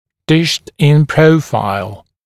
[dæʃt-ɪn ‘prəufaɪl][дэшт-ин ‘проуфайл]«птичий» профиль лица